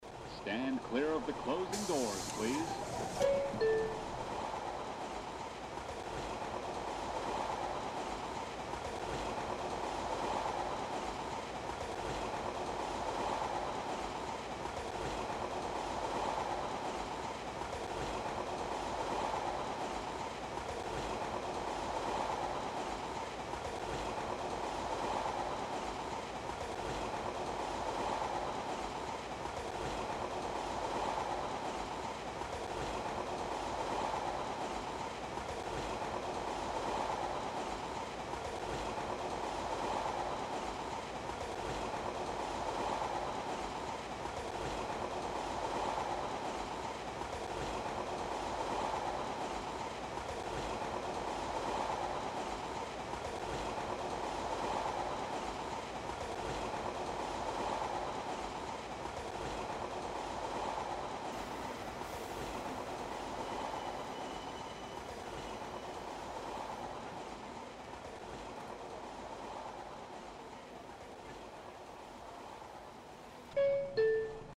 Supplementary audio-Sample audio played during trips